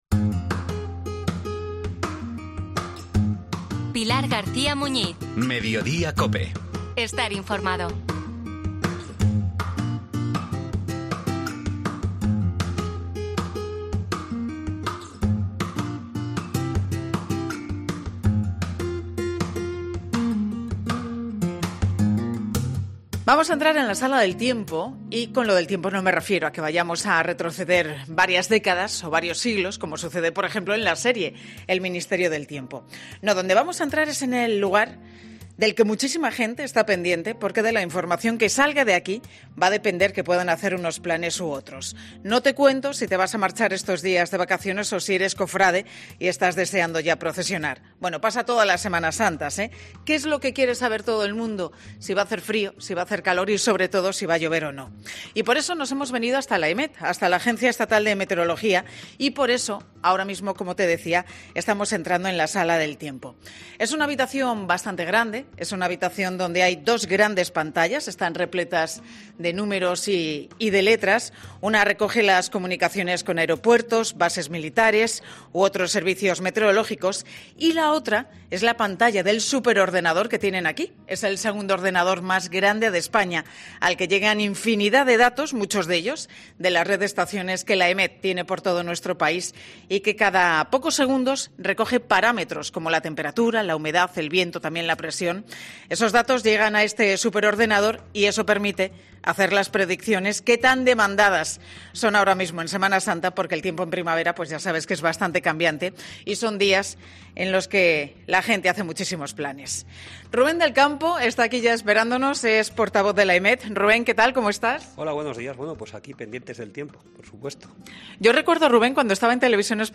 Y por eso, los de Mediodía COPE han visitado este Lunes Santo, la AEMET, la Agencia Estatal de Meteorología y ha sido allí, donde ha entrado en la sala del tiempo.